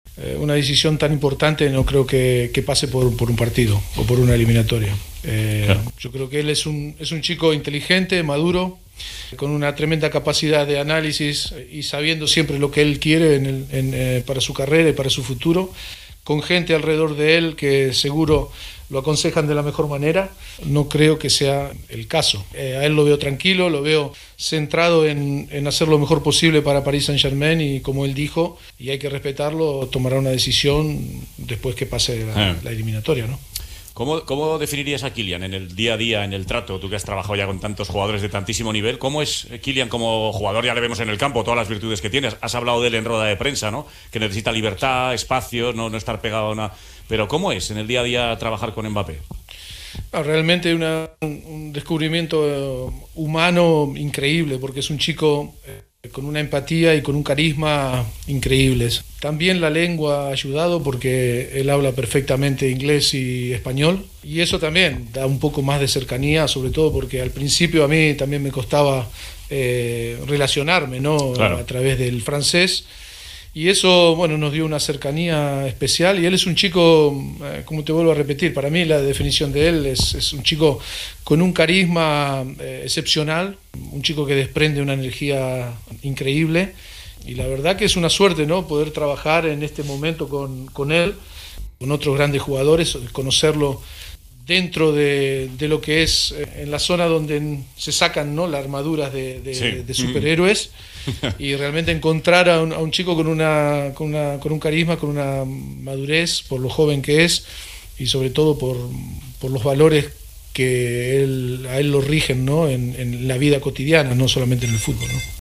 (Mauricio Pochettino, DT del PSG)